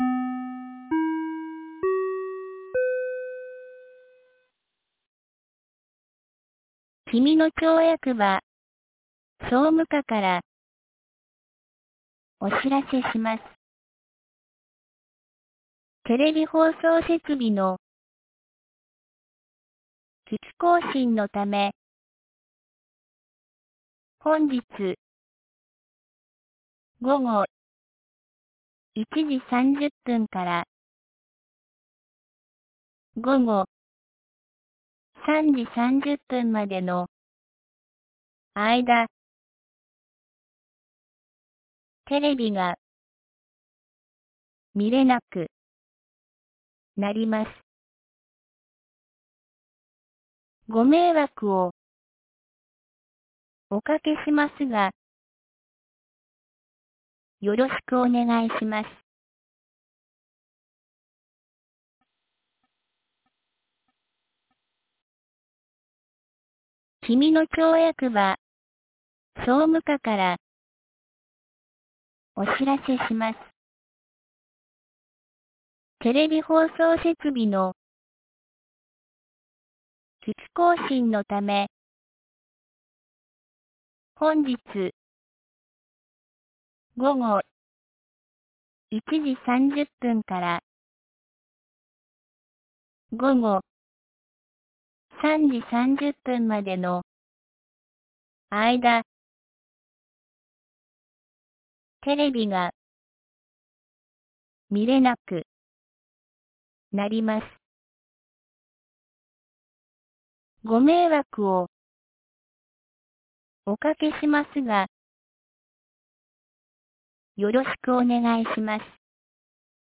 2022年11月11日 12時32分に、紀美野町より上神野地区へ放送がありました。